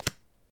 card3.ogg